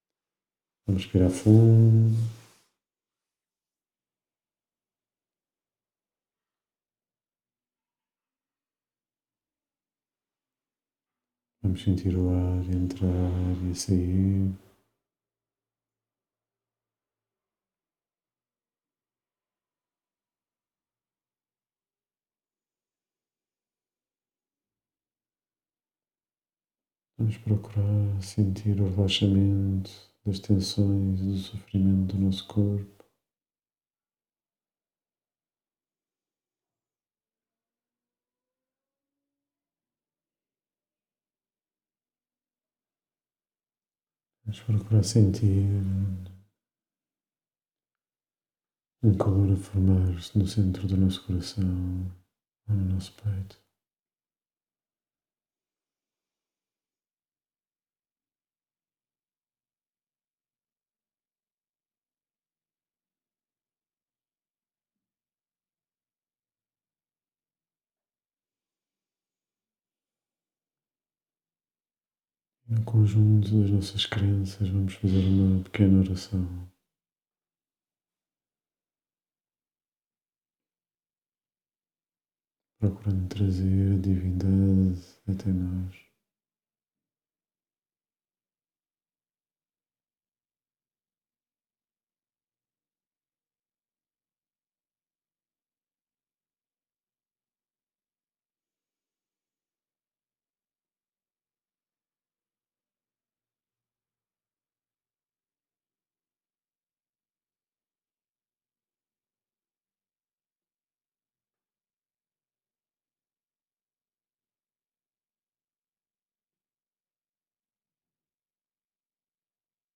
Meditação